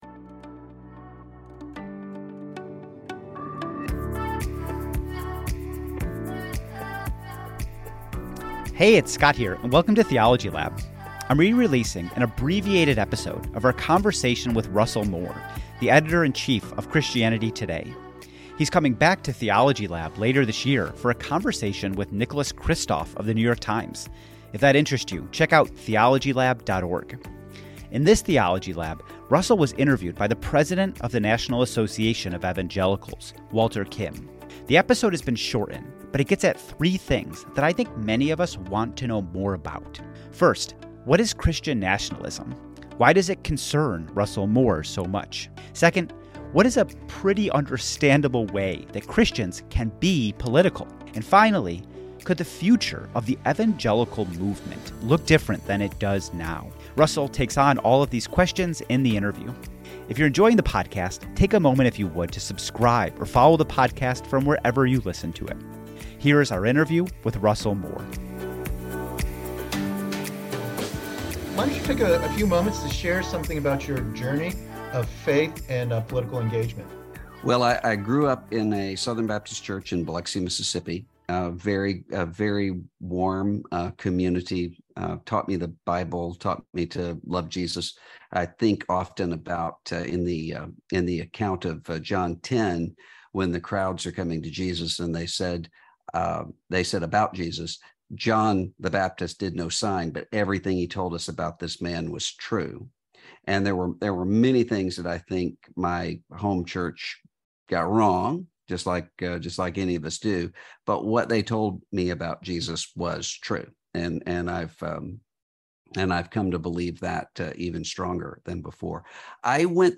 Recording of the American Evangelicalism Theology Lab session from November 11, 2022 with guest, Rev. Dr. Russell Moore (Christianity Today).
In this discussion, moderated by Rev. Dr. Walter Kim (president, National Association of Evangelicals), Russell Moore shares some personal stories about his relation to faith and political engagement. Russell speaks to the looming issue of Christian Nationalism, and what it means for Christians today to maintain both integrity and faithfulness in the public sphere.